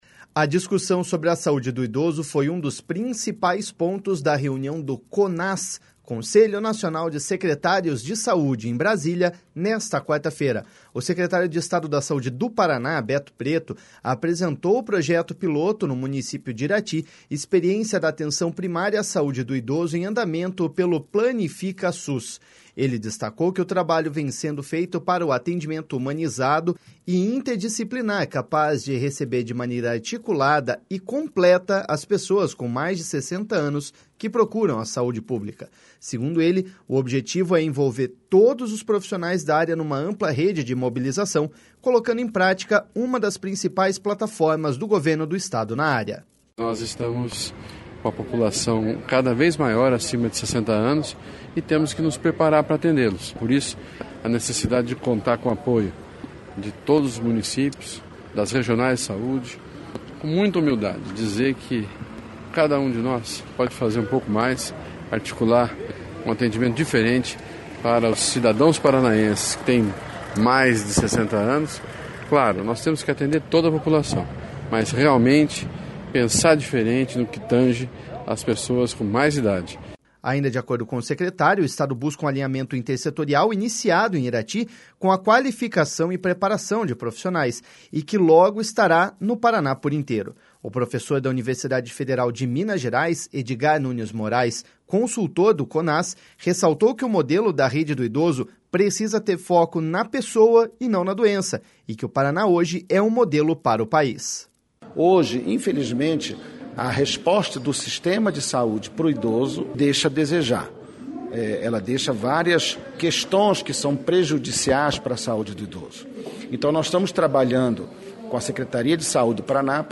Segundo ele, objetivo é envolver todos os profissionais da área numa ampla rede de mobilização, colocando em prática uma das principais plataformas do Governo do Estado na área.// SONORA BETO PRETO.//